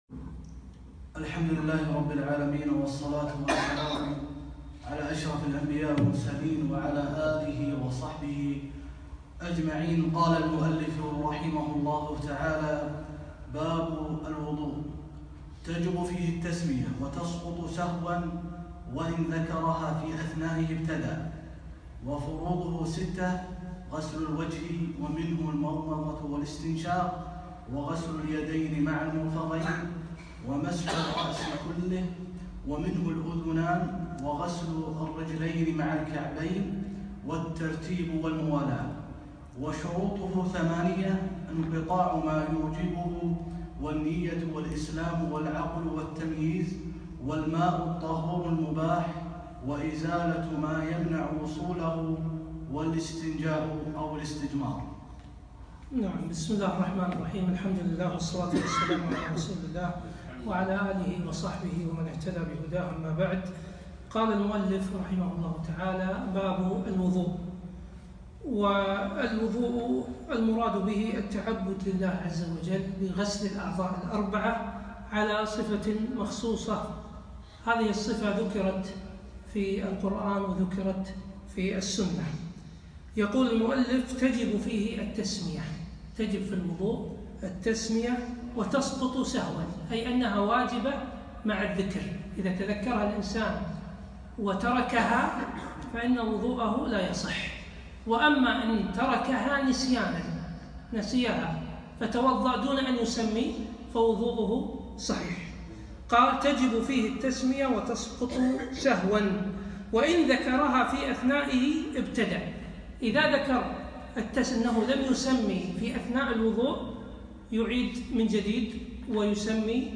يوم السبت 11 رجب 1438 الموافق 8 4 2017 في مسجد ضاحية العارضية العارضية
3- شرح باب الوضوء و باب المسح على الخفين